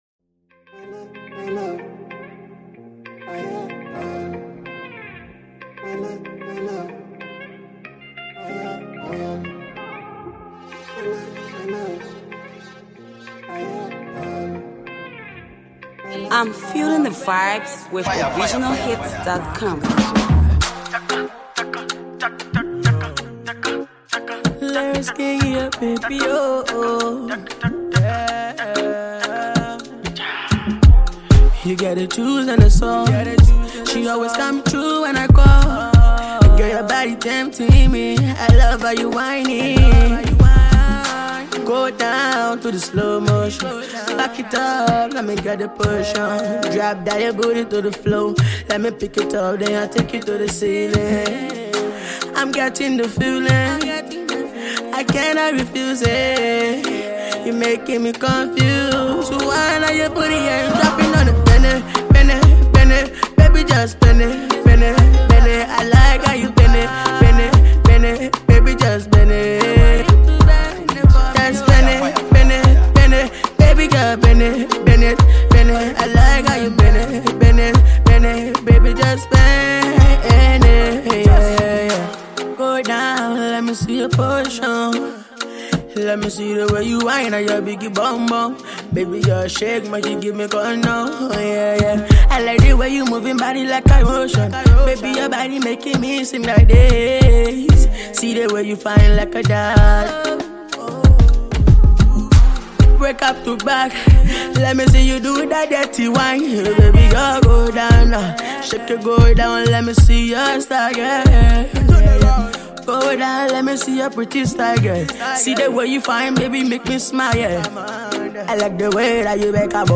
AfroAfro PopMusic